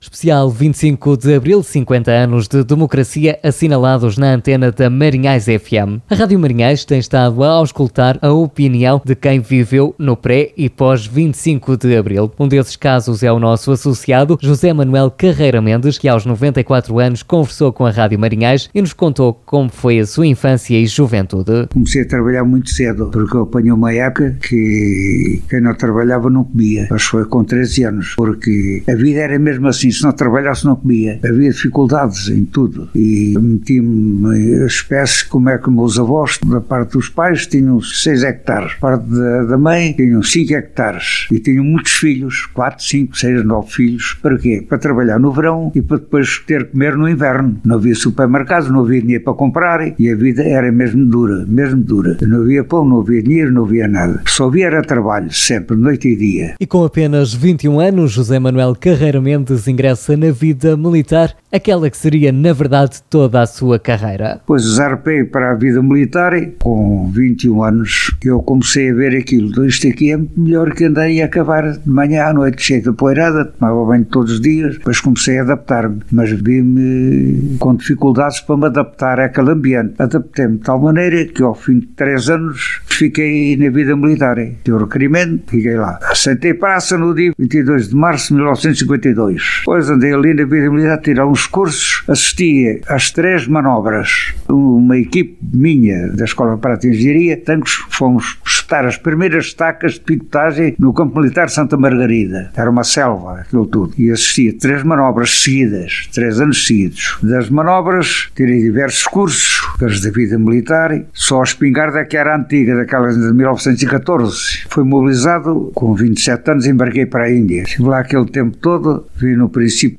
No âmbito das comemorações dos 50 anos do 25 de abril de 1974, a Rádio Marinhais realizou uma série de entrevistas com autarcas da região, mas também com figuras com peso na vila de Marinhais